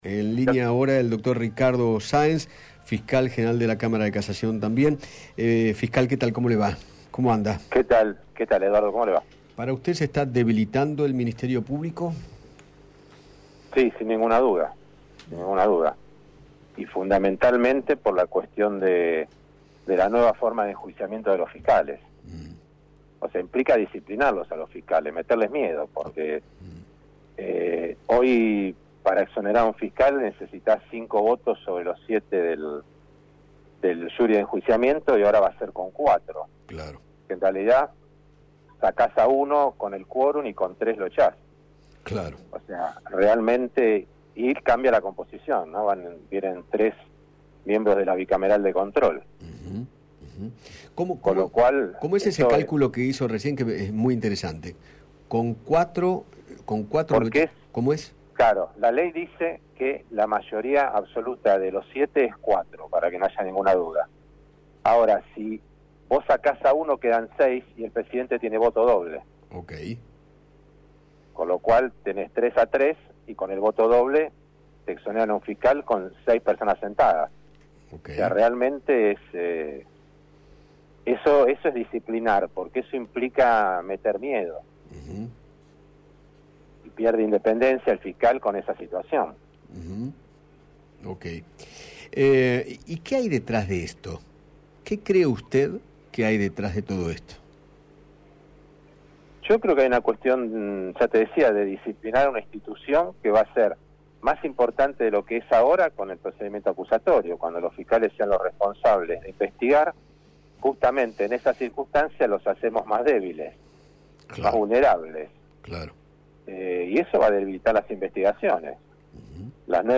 Eduardo Feinmann dialogó con los fiscales Raúl Pleé y Ricardo Sáenz sobre el proyecto de ley aprobado en el Senado, que modifica la ley orgánica del Ministerio Público Fiscal. Ambos se mostraron preocupados por la iniciativa y coincidieron en que se somete a la Justicia a los intereses del poder de turno.